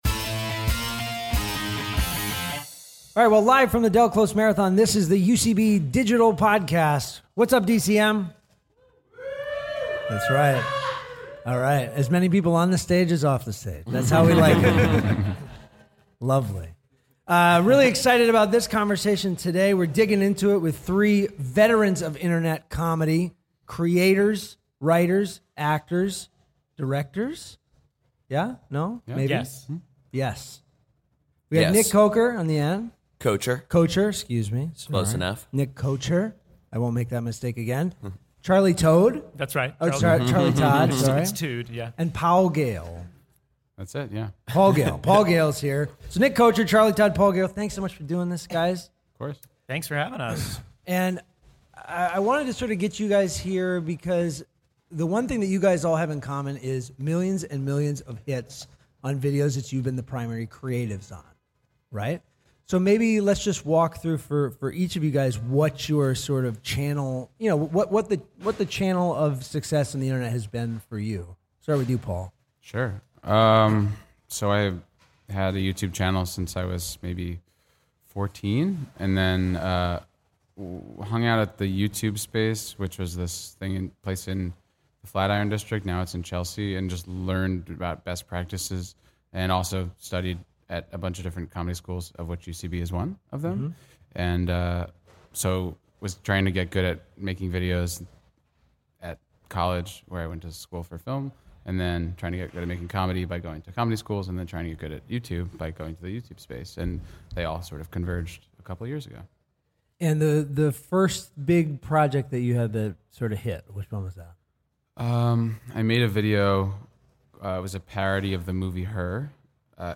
Recorded live in New York City during the 18th annual Del Close Marathon.